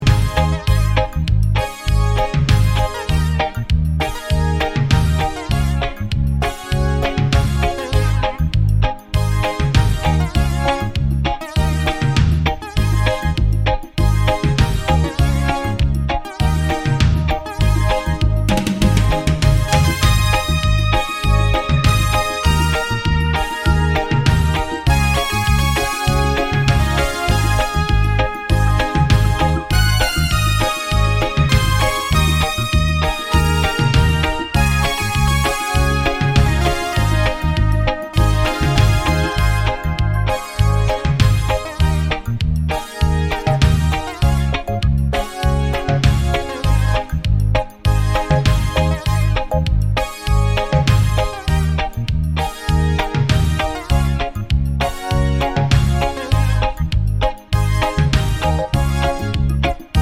no Backing Vocals Reggae 5:04 Buy £1.50